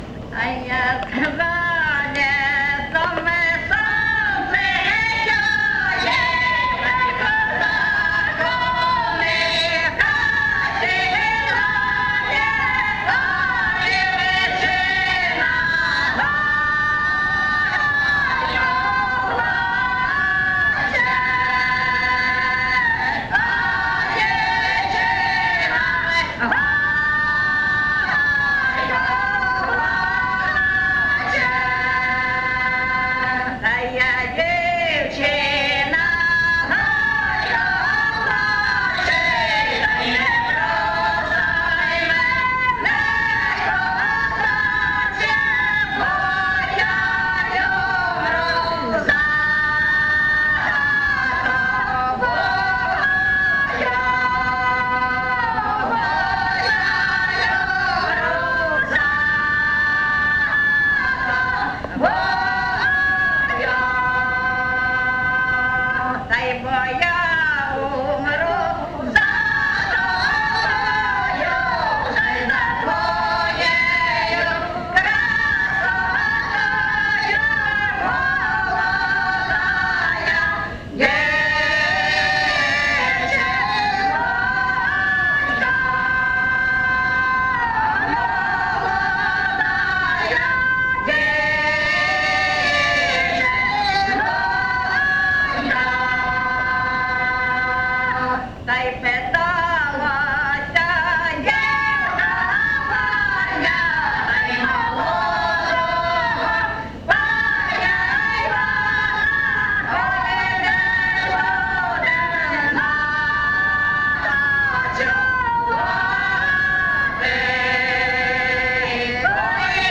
ЖанрПісні з особистого та родинного життя, Козацькі
Місце записус. Очеретове, Валківський район, Харківська обл., Україна, Слобожанщина